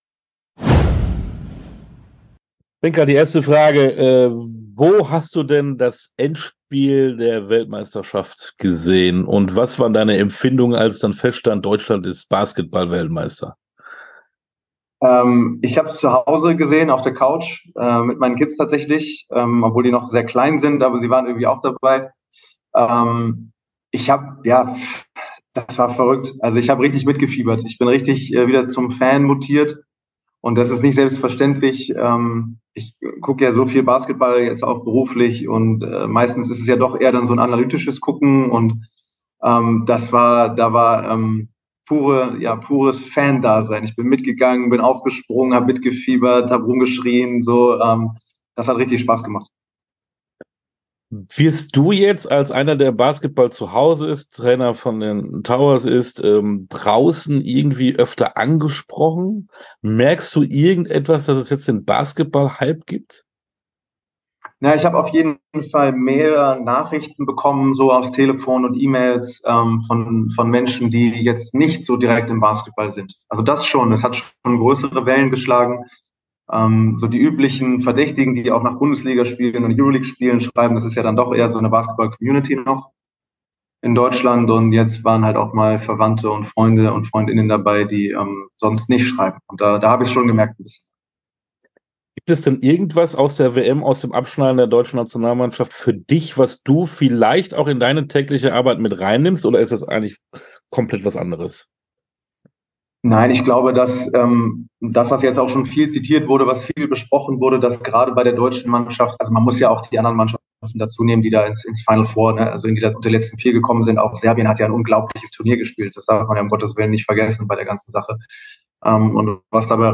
Sportstunde - Interviews in voller Länge Podcast